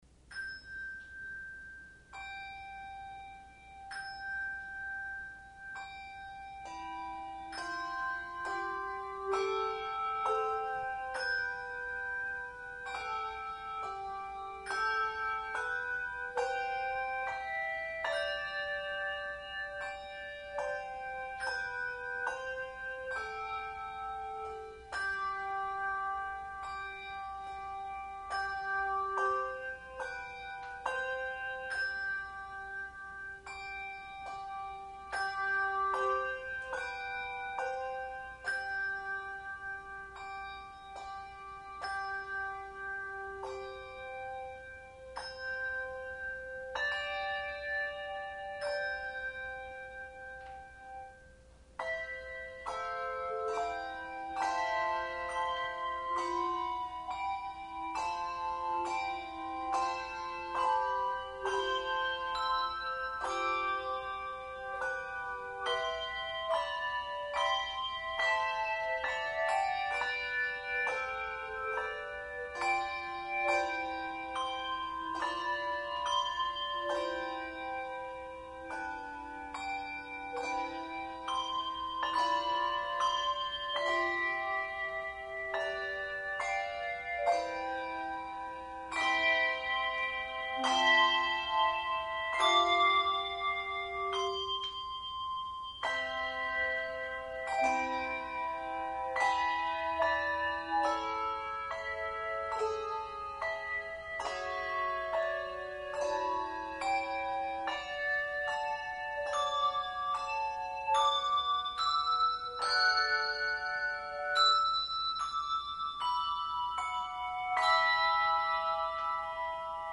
Handbell Quartet
Genre Sacred
No. Octaves 3 Octaves